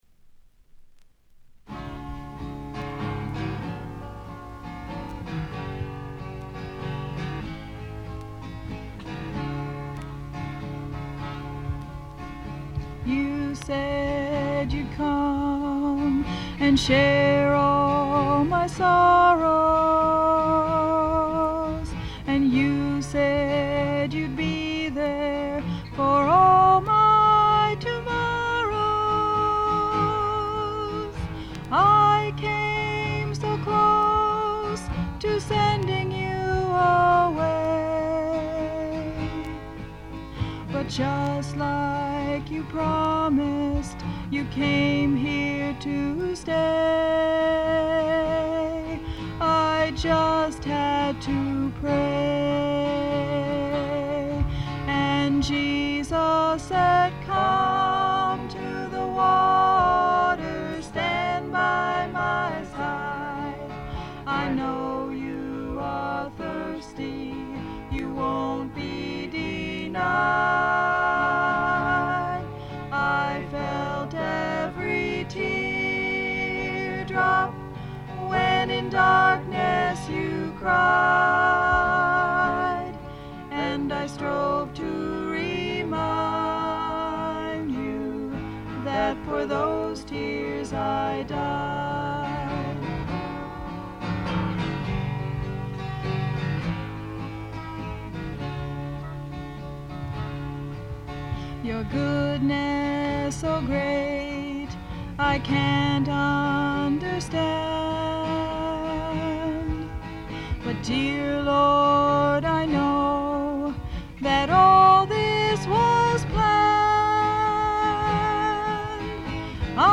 知られざるクリスチャン・フォーク自主制作盤の快作です。
試聴曲は現品からの取り込み音源です。